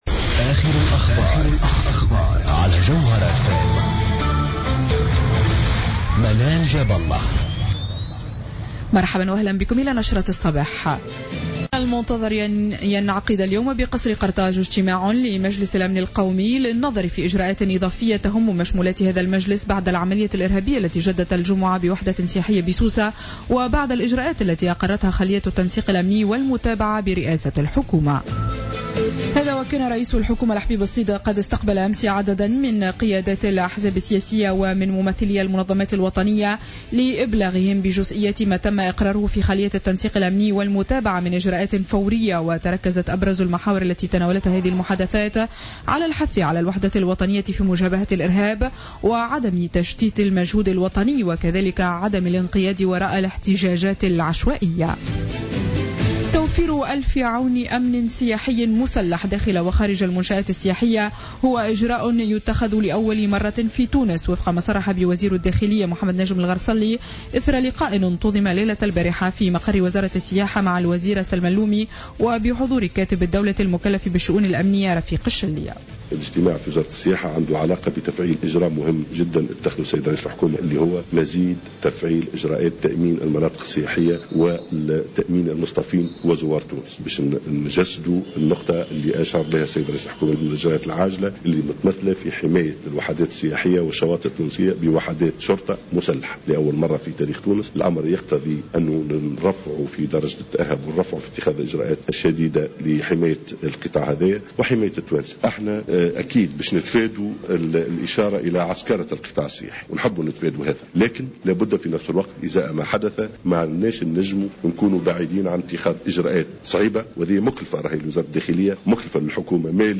نشرة أخبار السابعة صباحا ليوم الأحد 28 جوان 2015